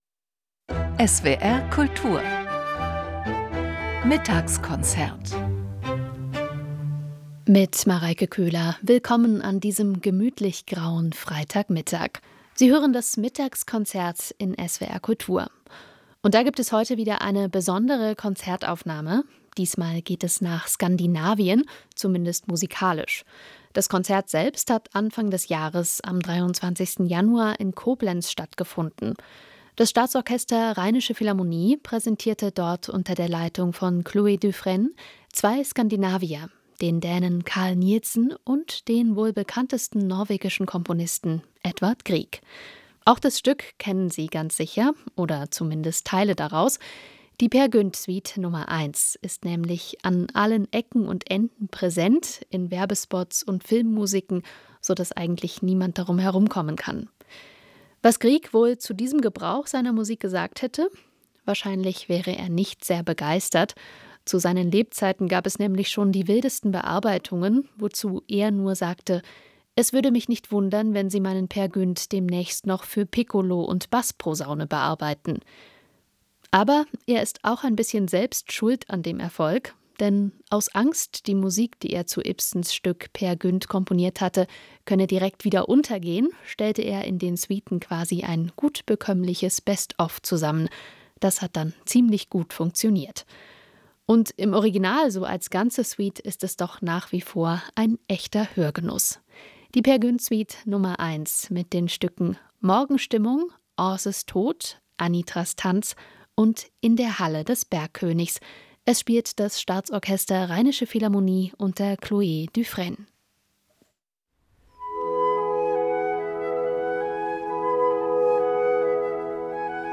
Zwei Skandinavier, kaum verschiedener denkbar: Edvard Grieg und Carl Nielsen, dessen Klarinettenkonzert selbst geübte Hörerinnen und Hörer immer wieder überrascht.